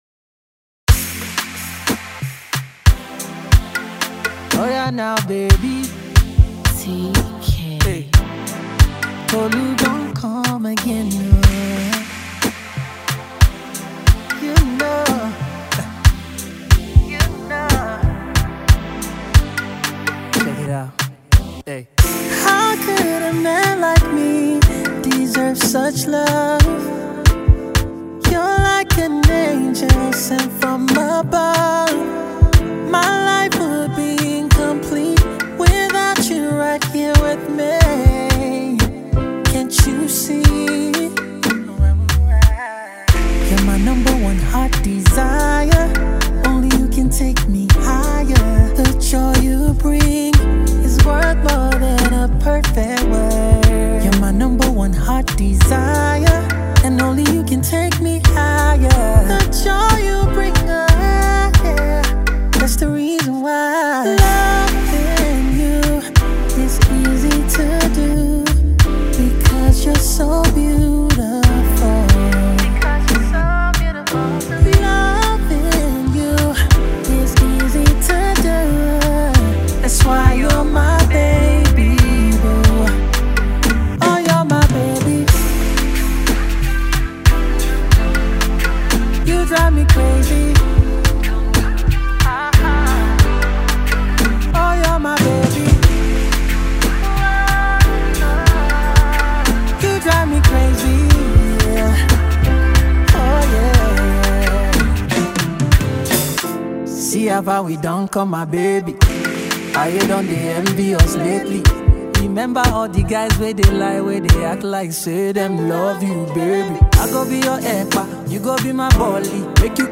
Birthday boy and Nigerian R&B singer
blend of R&B and AfroBeat song